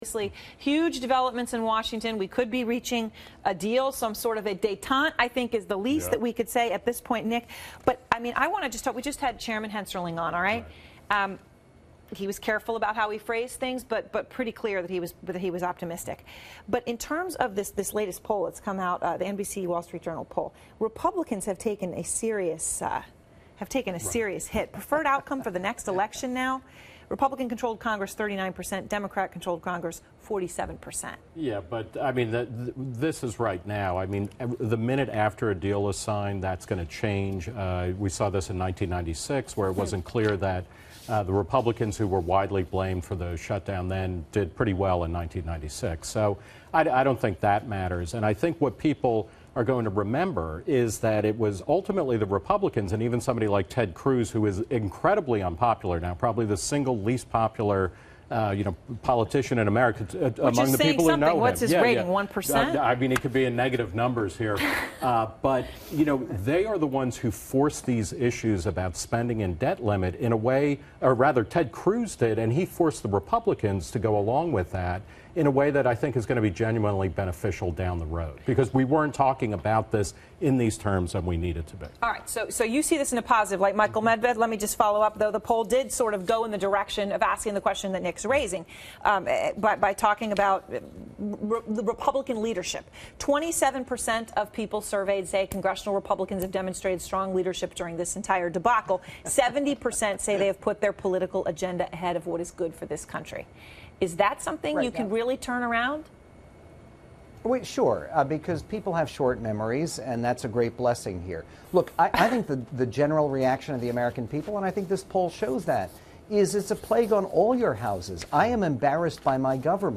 Reason Magazine's Nick Gillespie appeared on CNN's Out Front with Erin Burnett to discuss the Debt Ceiling and the continuing Shutdown